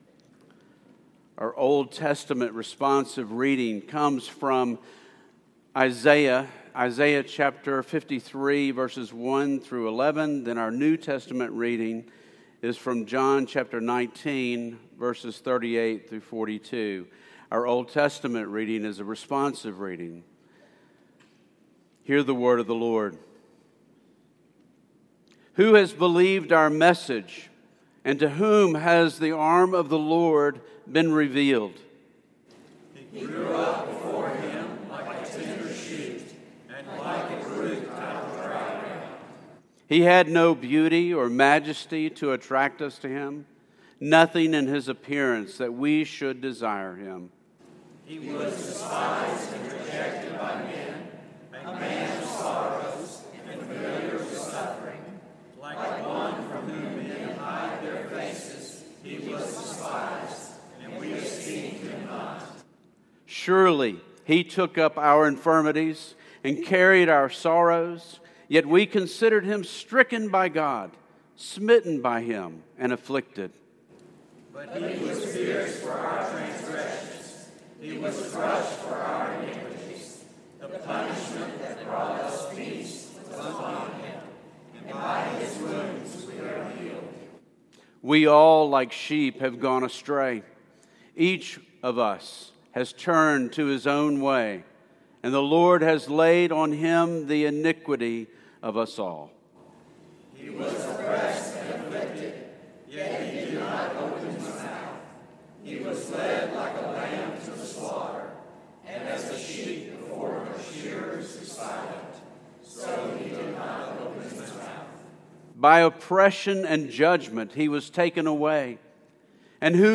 This sermon is part of the following series: